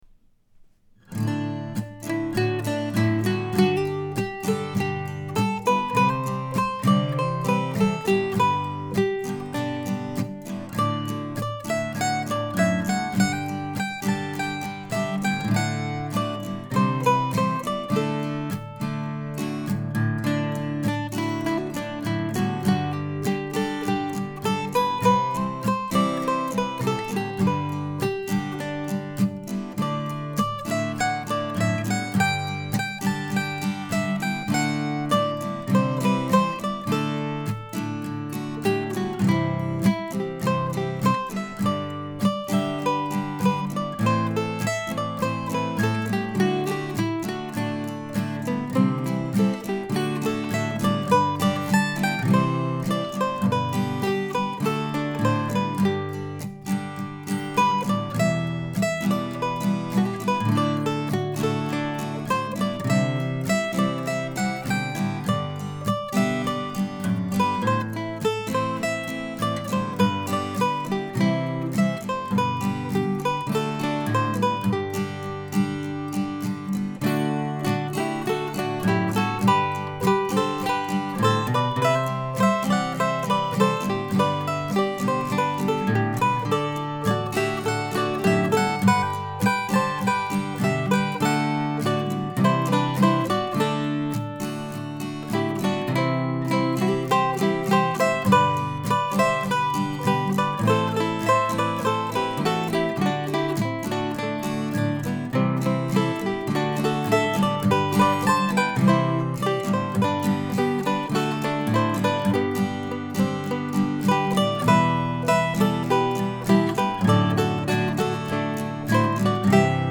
There are three parts to the tune but I repeated the A part the first time through and not the other two times. It's a slow, leisurely walk.